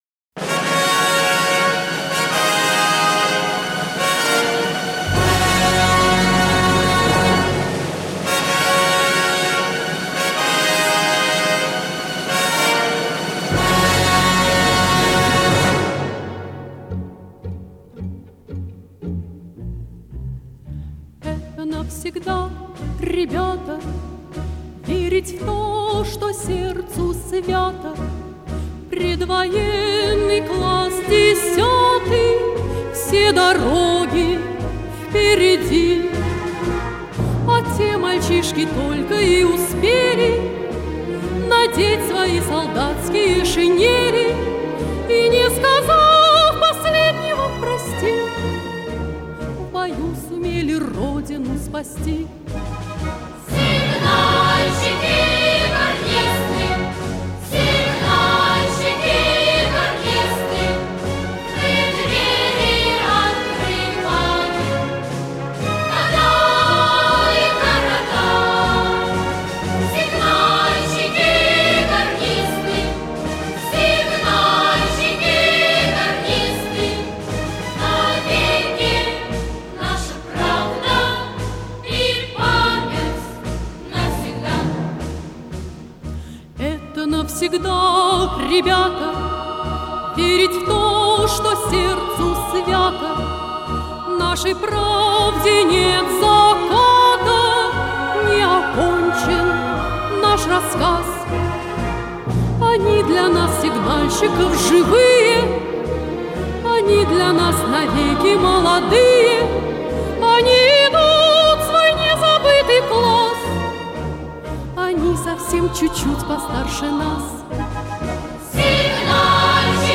солистка